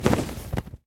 wings1.ogg